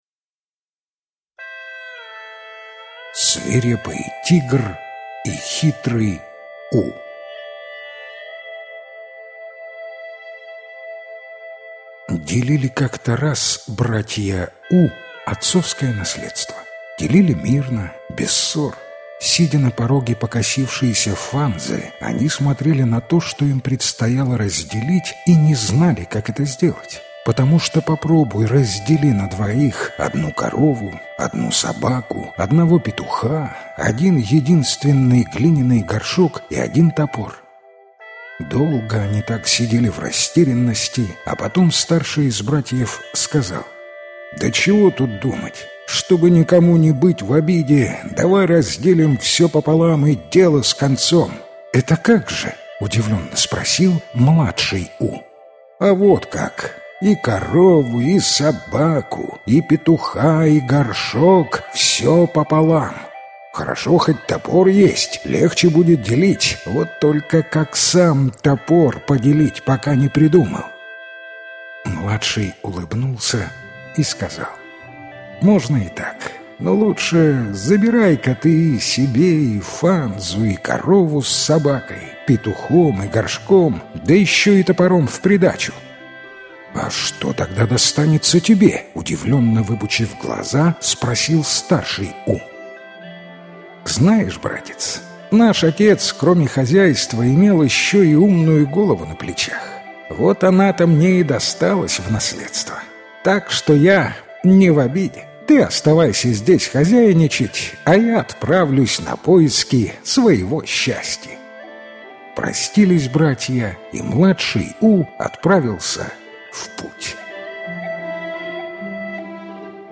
Свирепый тигр и хитрый У - китайская аудиосказка - слушать